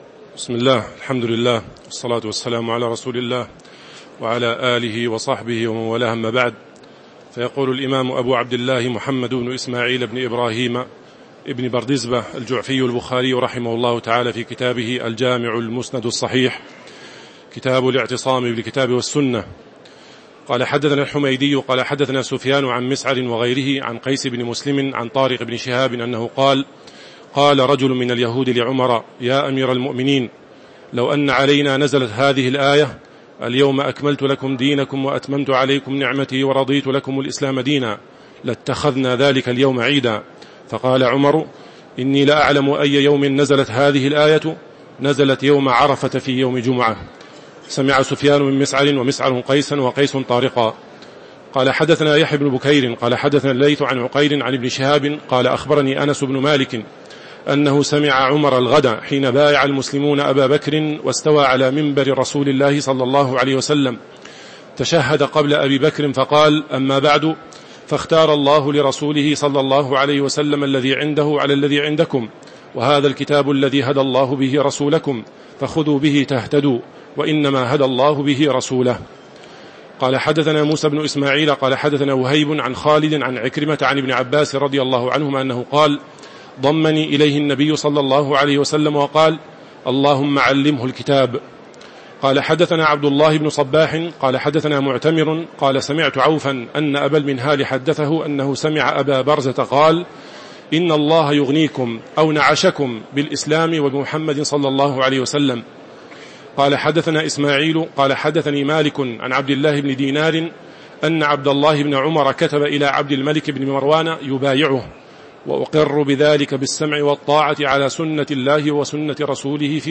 تاريخ النشر ١٤ جمادى الآخرة ١٤٤٦ هـ المكان: المسجد النبوي الشيخ